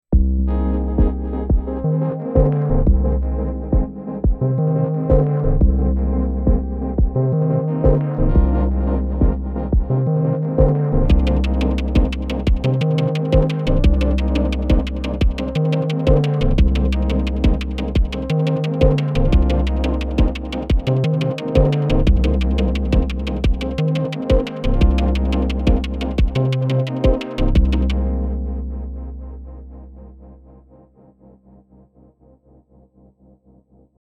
I went for quantity over quality this time :sweat_smile: This is all over the place, but quite firmly in the melodic/tonal camp.
Not sure how to describe this 170 bpm thing.